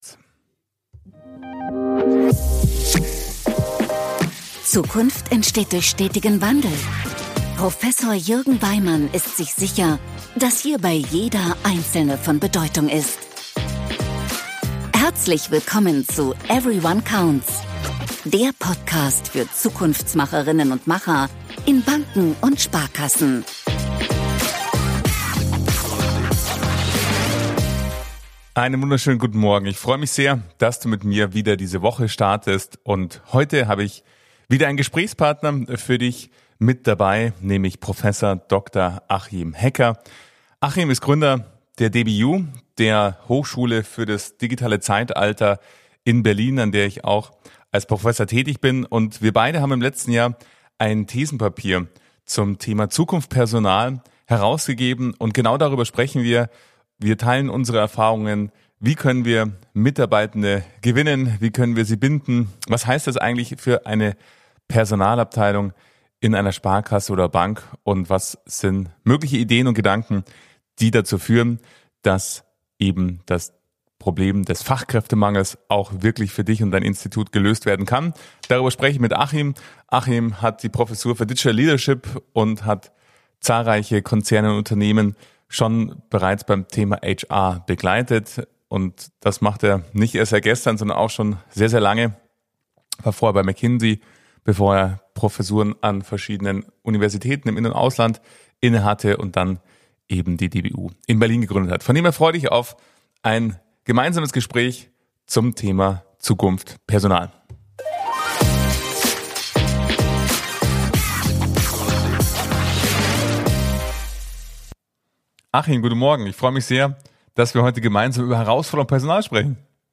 Wie sieht die Personalarbeit der Zukunft aus? Gespräch